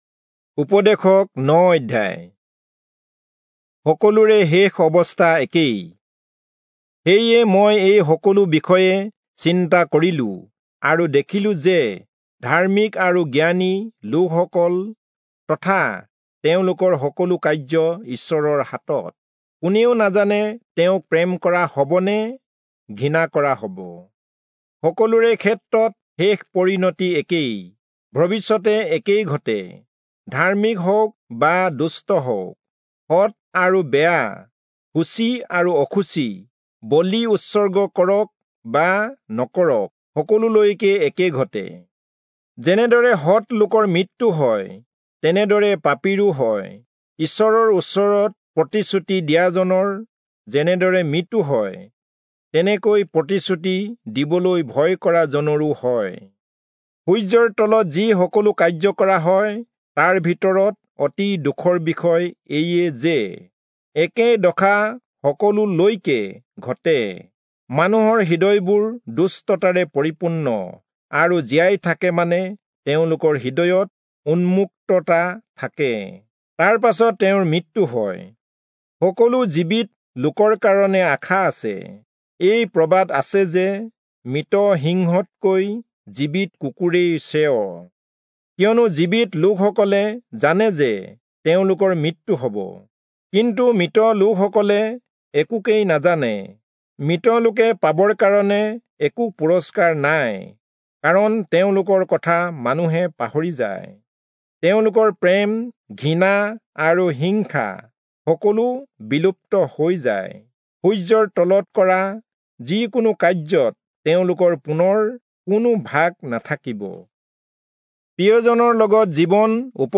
Assamese Audio Bible - Ecclesiastes 3 in Irvte bible version